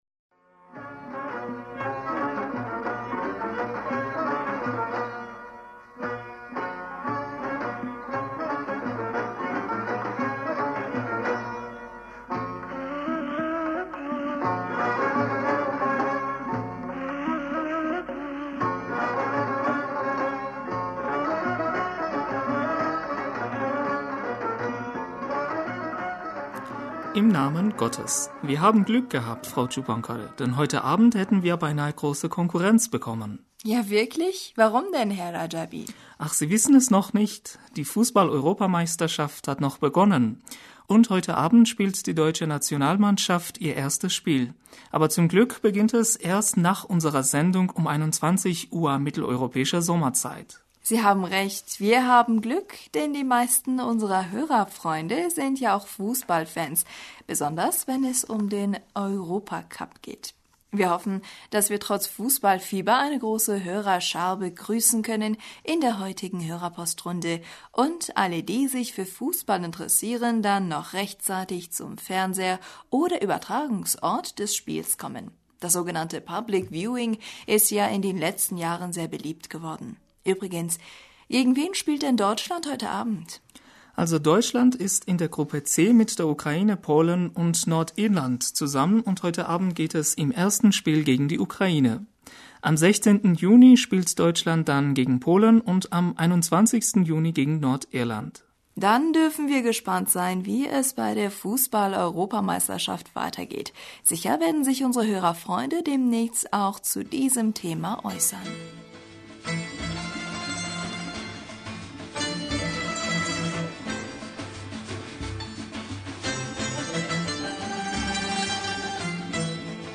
Hörerpostsendung